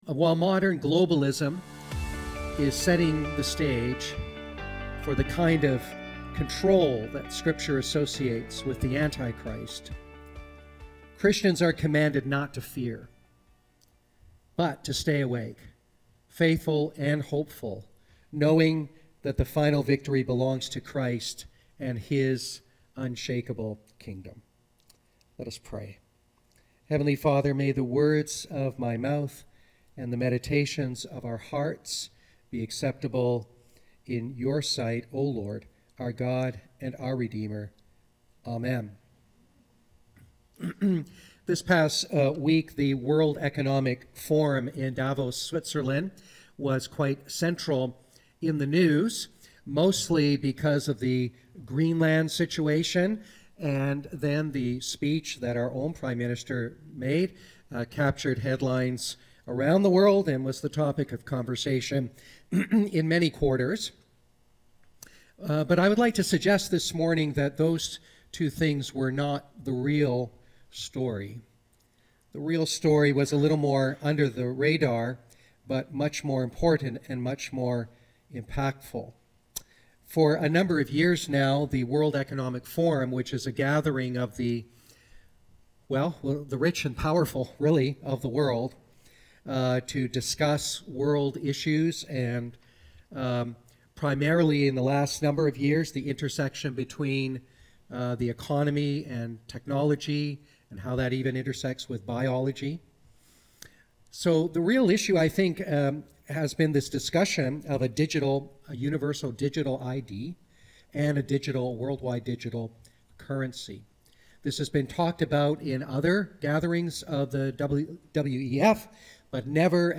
Non-Series Sermons